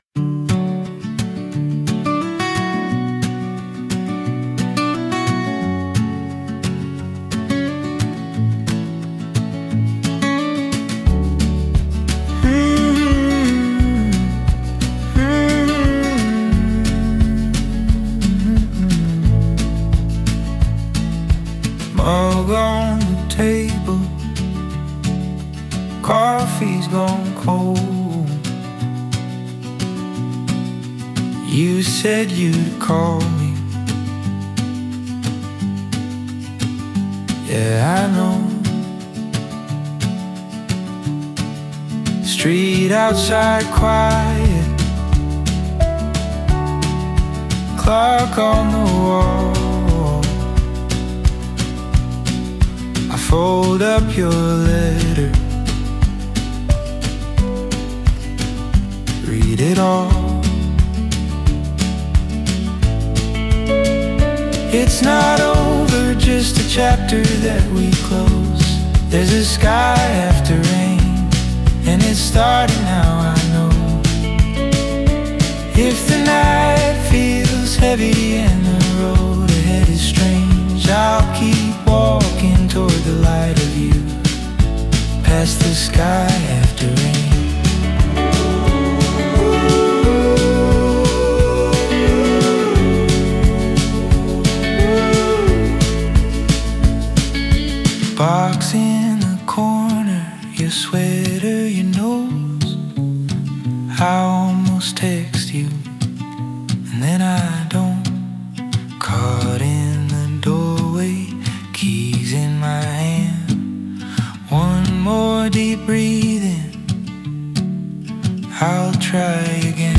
Indie/Alternative 2026 Non-Explicit